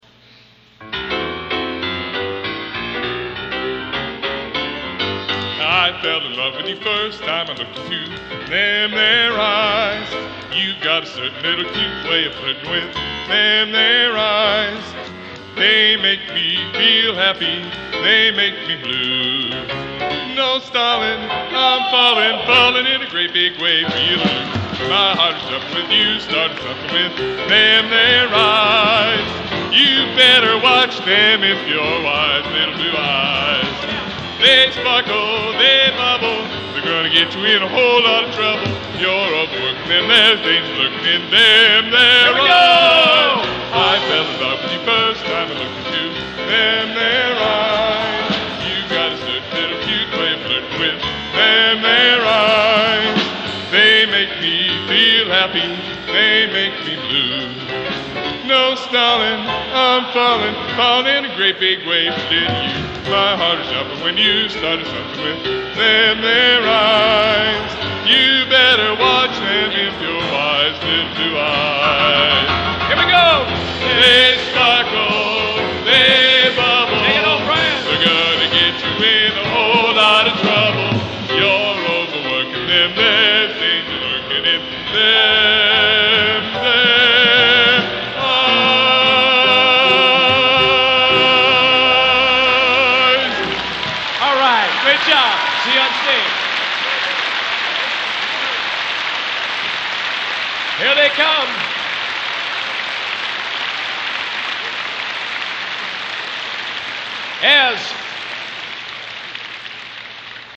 Genre: Popular / Standards | Type: Runout